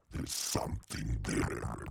• demonic techno voice "there's something there".wav
Changing the pitch and transient for a studio recorded voice (recorded with Steinberg ST66), to sound demonic/robotic.